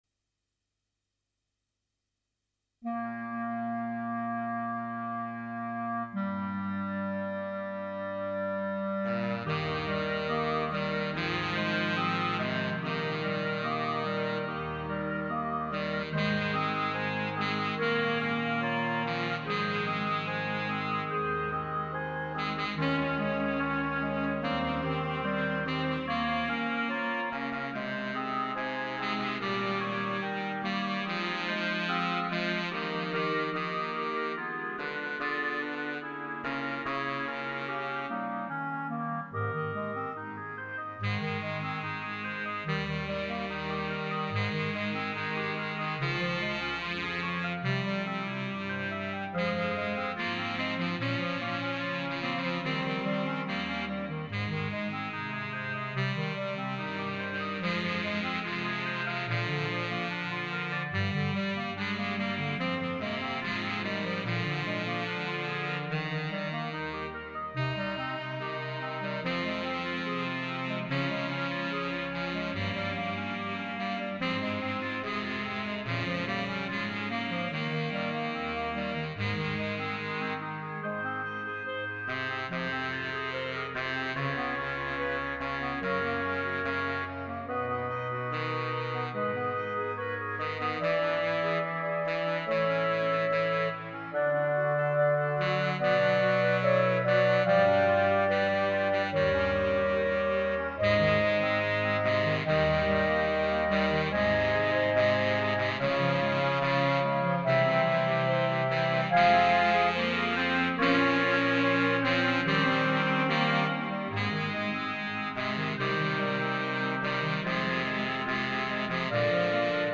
Instrumentation: 4 Cl. Bb,Alto Cl, Bass Cl,
Alto Sax, Tenor Sax.
A sumptuous clarinet and saxophone arrangement